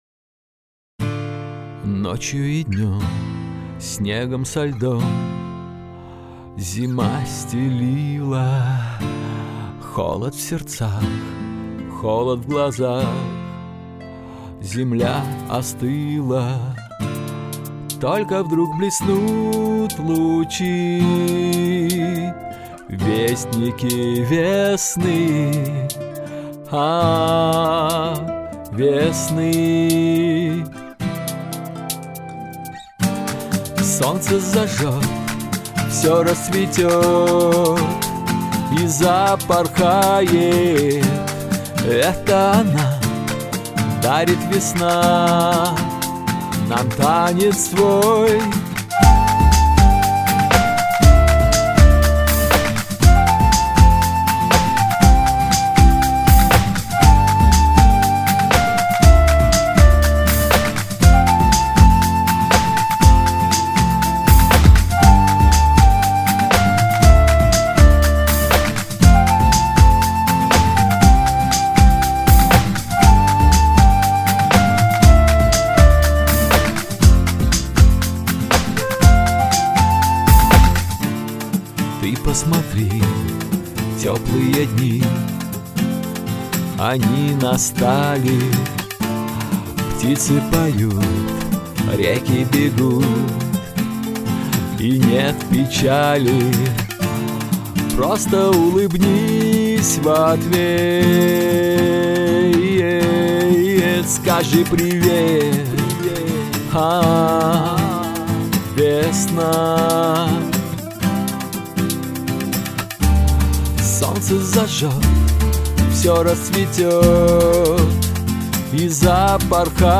Звукорежиссура отсутствует.
Понятно, что не хватает даблов и бэков, но на этом нюансы не заканчиваются) Буду очень благодарен за любую конструктивную и субъективную критику